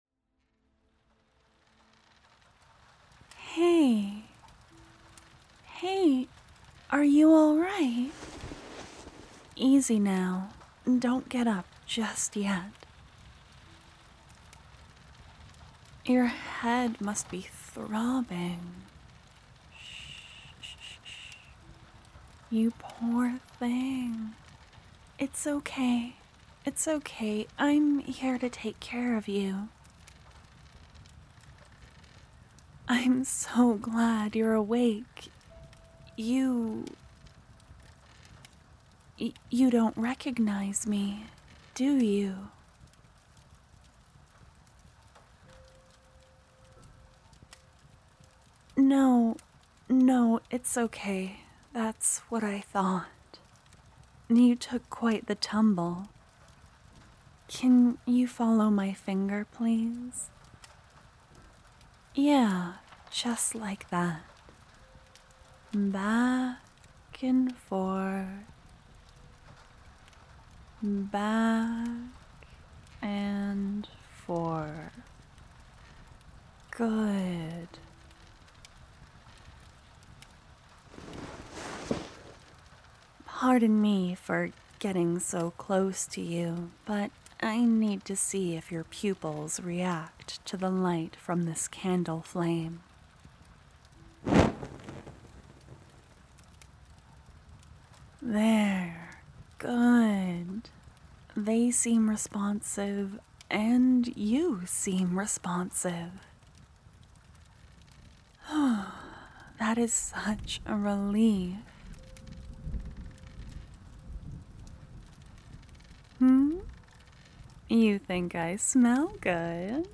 One of the most soothing voices I know.